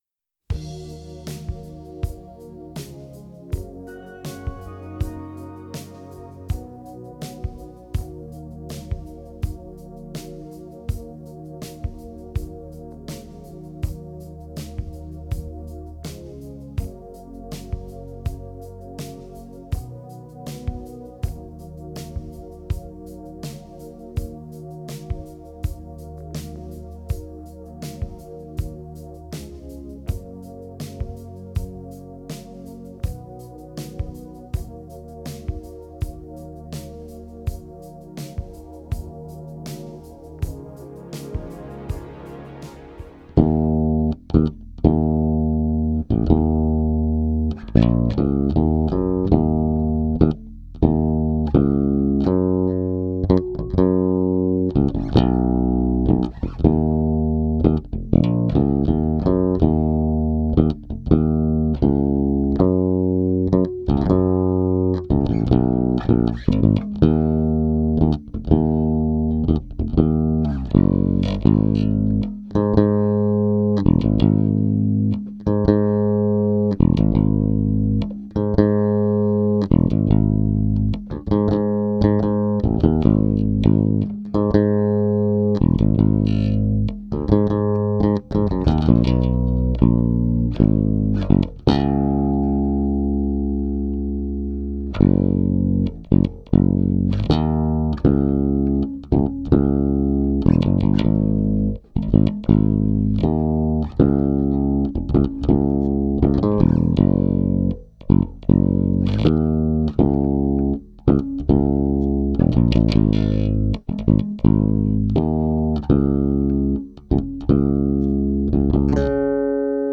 nur bass